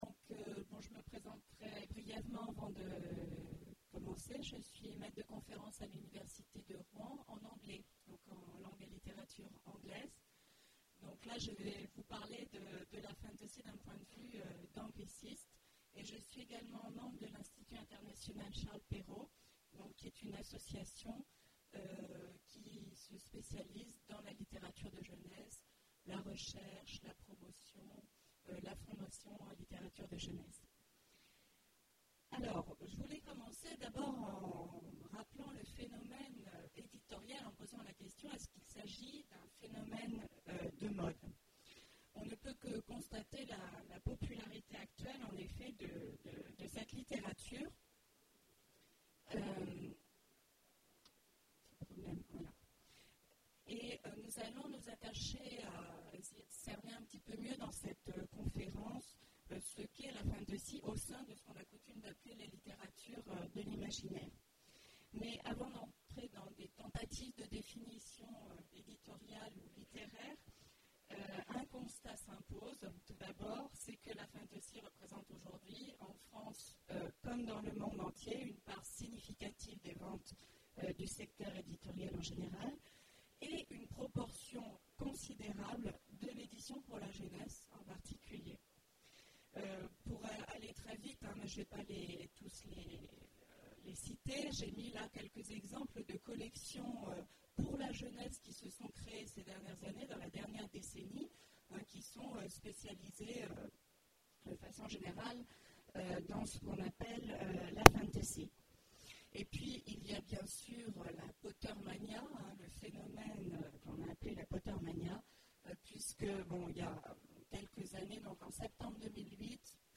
Futuriales 2011 : Conférence La fantasy britannique pour la jeunesse d’hier à aujourd’hui
Lors du festival des Futuriales 2011